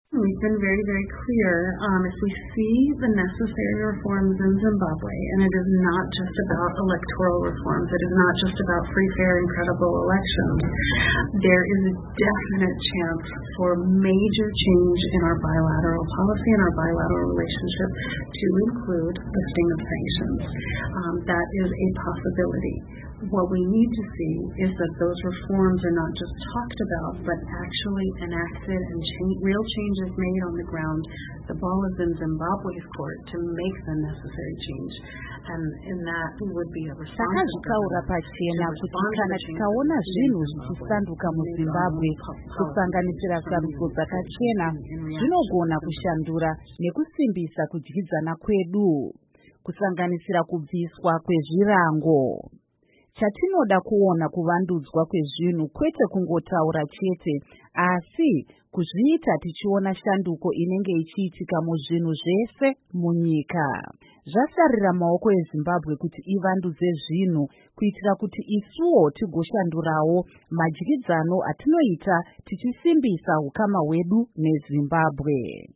Vachitaura pamusangano wavakaita nevatori venahu veStudio7 neChishanu mu Washington DC, Amai Savage vakati America inoda zvikuru kudyidzana neZimbabwe, uye ushuviro yavo yekuti Zimbabwe igadzirise zvinhu zviri kunetsa kuitira kuti itambirwe nenyika dzepasi rose.
Amai Jennifer Savage Vachitaura